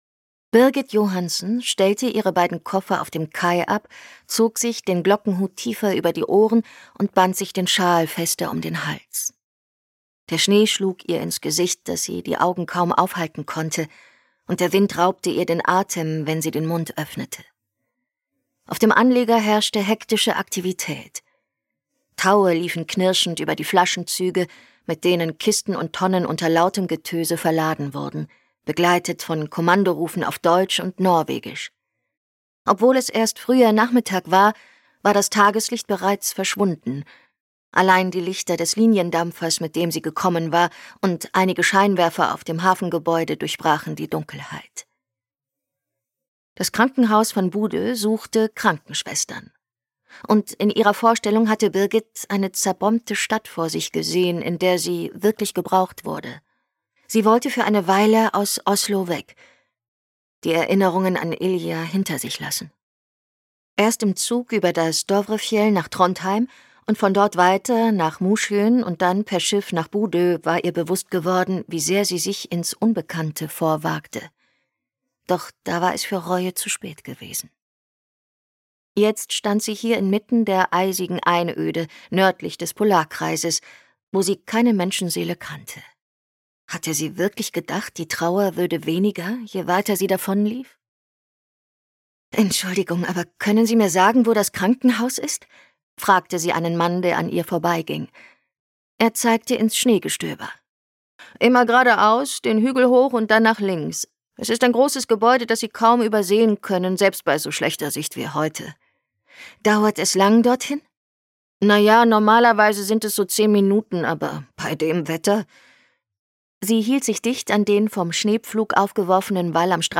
Als Hörbuchsprecherin besticht sie mit ihrer frischen, jugendlichen Stimme und hat unter anderem die Bestseller-Romane von Sarah Lark gelesen.